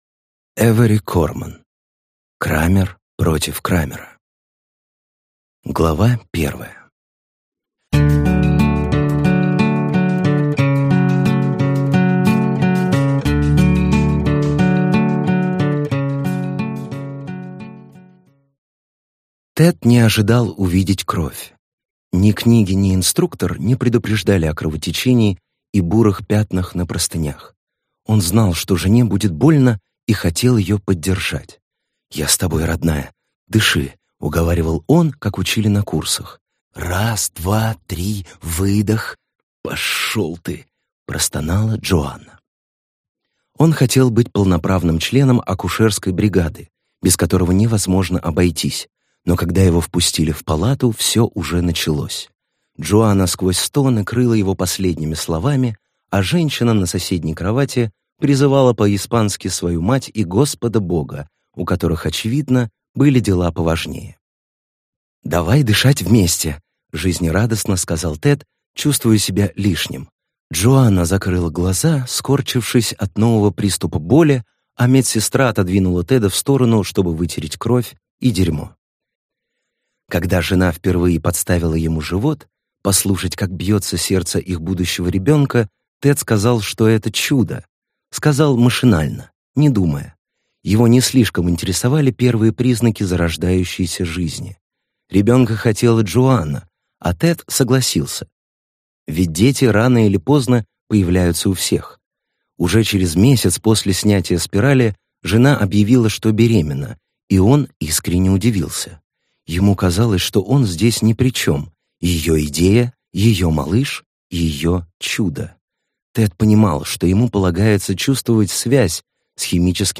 Аудиокнига Крамер против Крамера | Библиотека аудиокниг